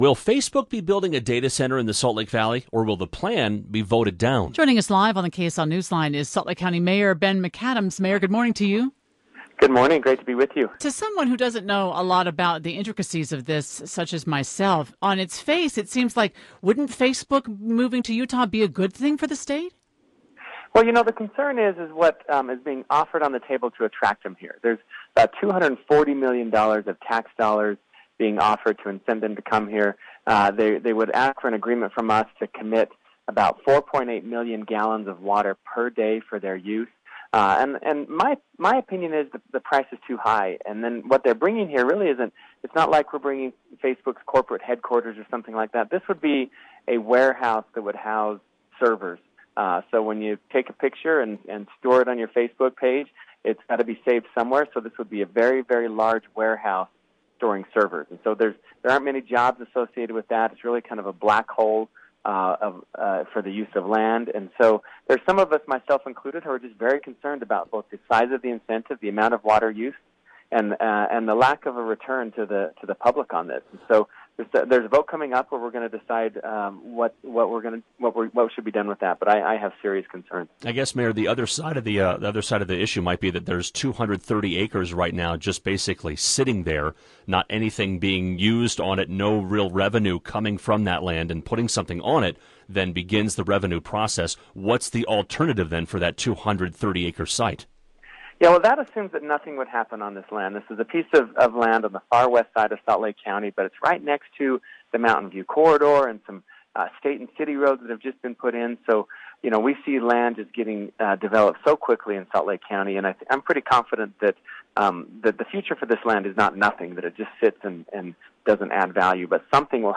Ben McAdams talks about Facebook data center on Utah's Morning News